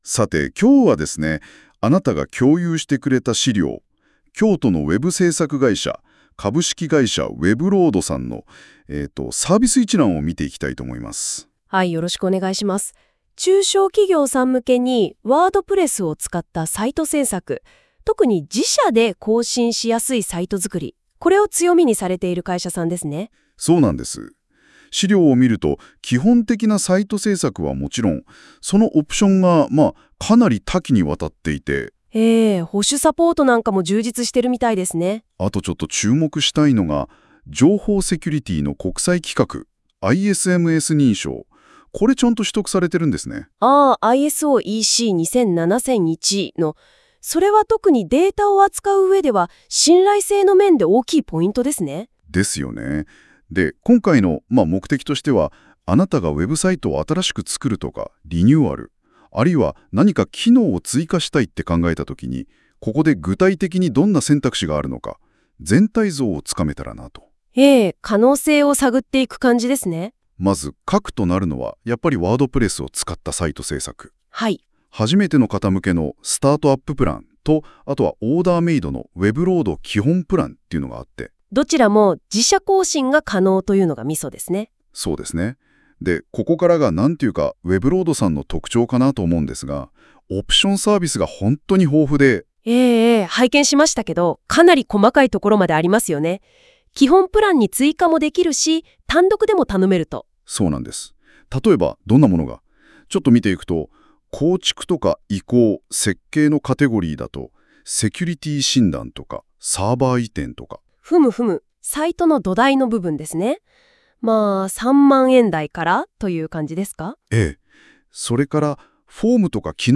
GoogleのNotebookLM～生成AI同士の衝撃の会話 | 京都のホームページ制作会社｜株式会社ウェブロード
弊社のホームページに各サービスのページがあるのですが、そのページのURLを資料として指定して「詳細な会話」の「生成」ボタンを押すと、下記のような音声が自動で作られます。まるでラジオです。
上記の音声は、下記ページのURLを指定して、自動生成しただけのものなのです。